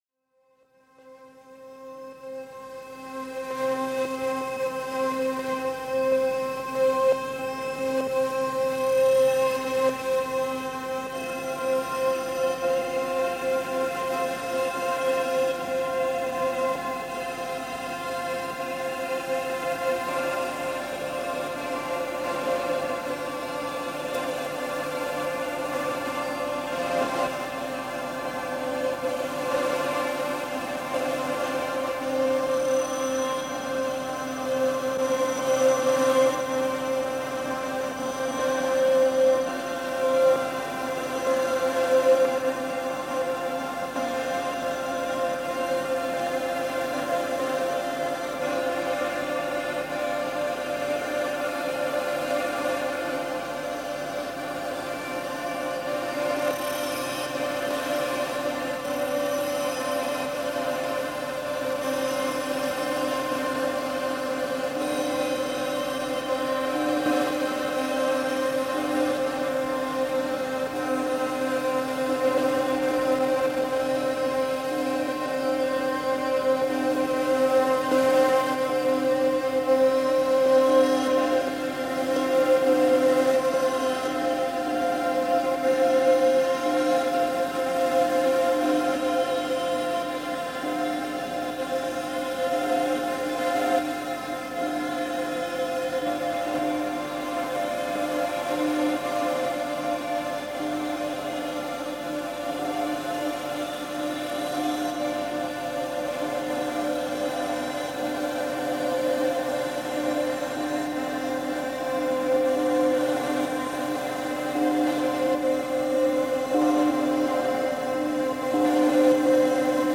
Mtskheta bell ringing reimagined